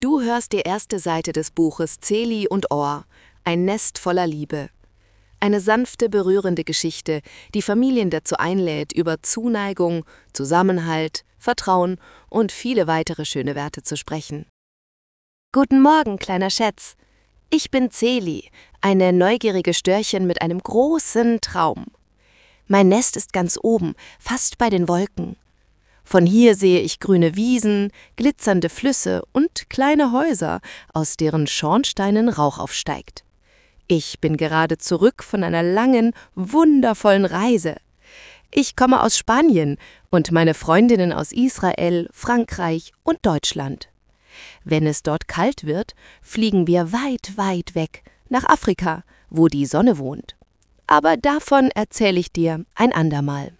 Lesungen (Audio)